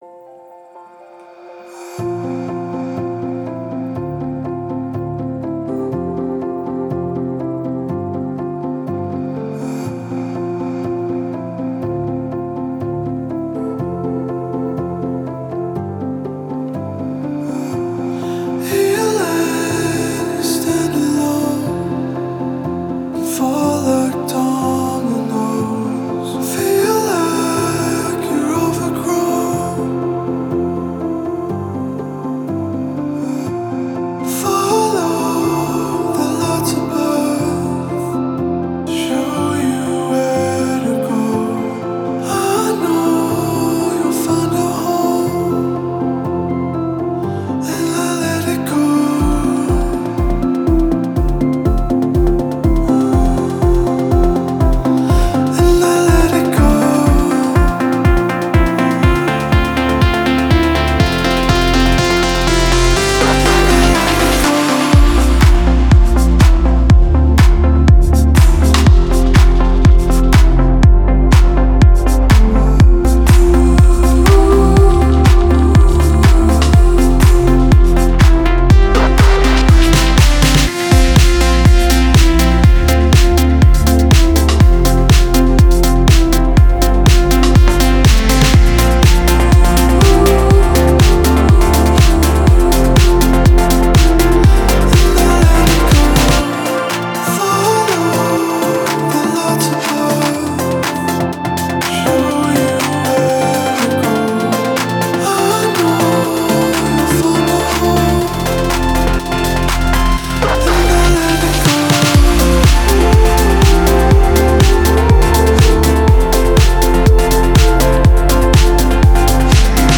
Жанр: Jazzdauren